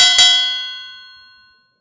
boxingRingBell.wav